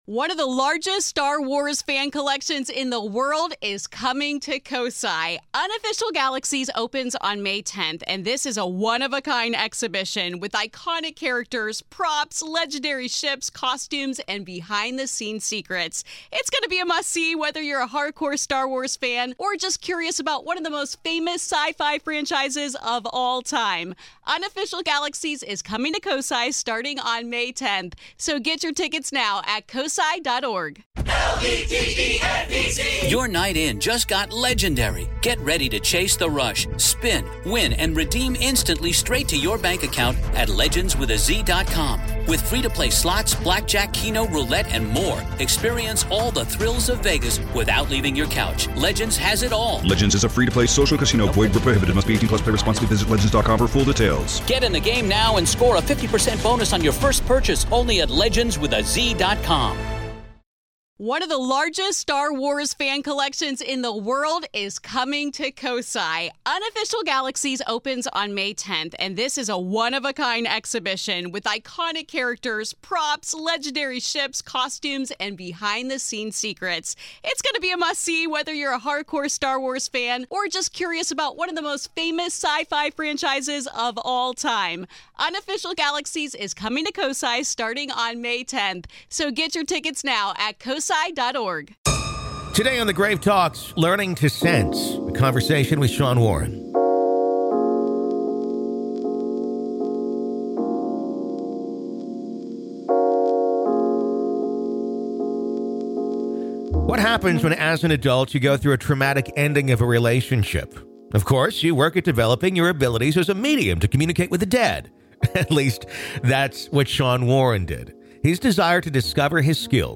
Haunted, Paranormal & Supernatural Stories